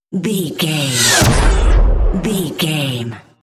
Whoosh speed with shot
Sound Effects
dark
intense
whoosh